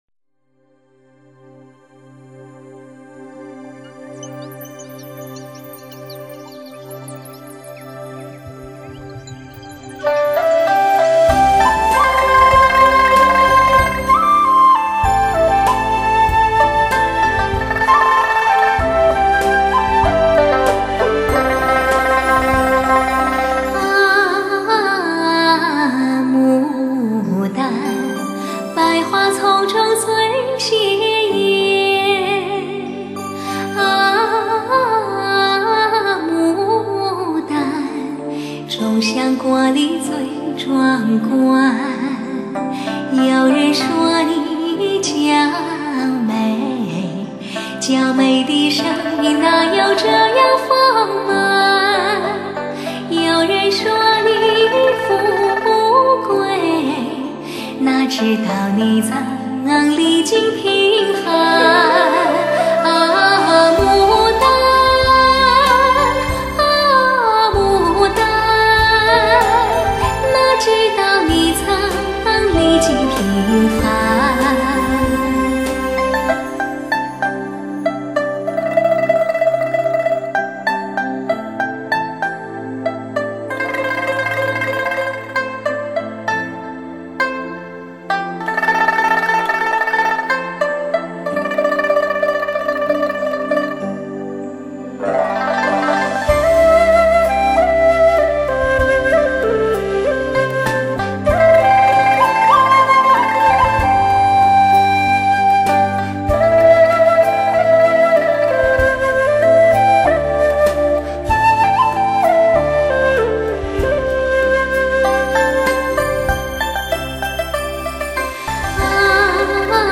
时而婉约，时而宏大，声声入耳，段段悠扬，轻吟浅唱时感人肺腑，
倾力而歌时绚丽煽情，不因为怀旧而忧伤哀怨，不因为回忆而失落消沉，
用鲜活的音色重新注解，在生命的跌宕中坚持特有的温馨柔美。